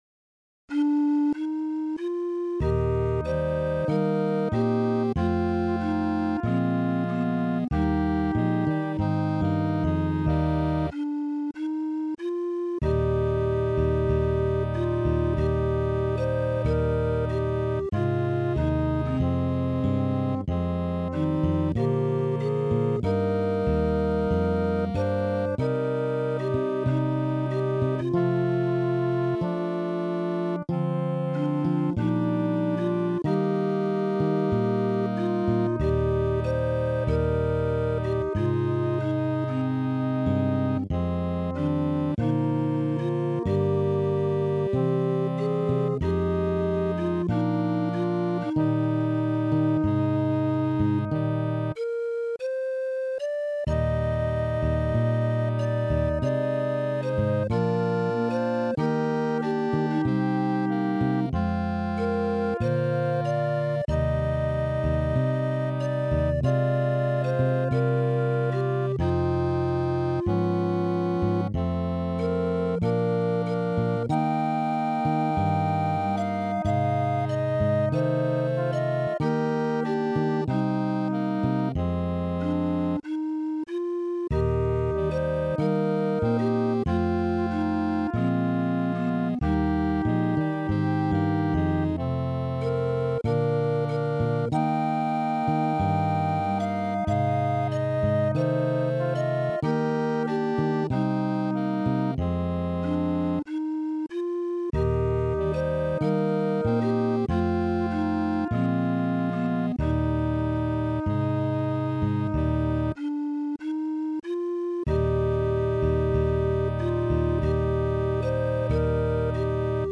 Tonality: E flat major